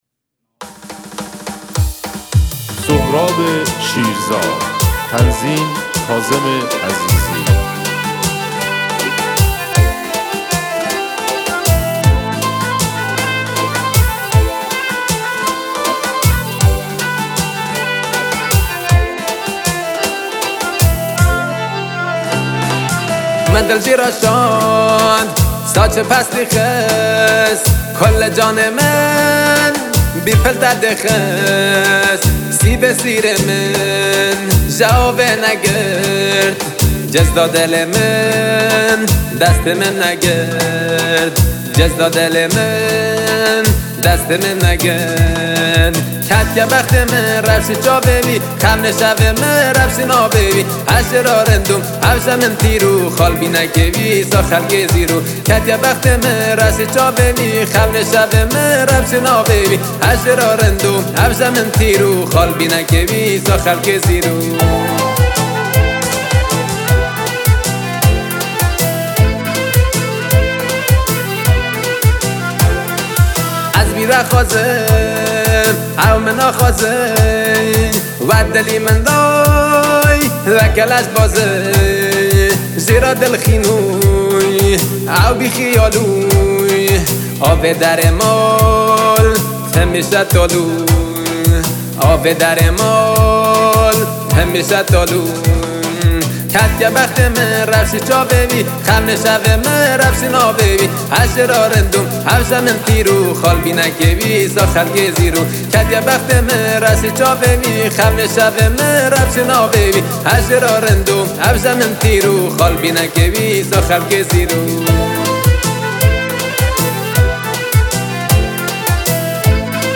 کرمانجی کیبوردی محلی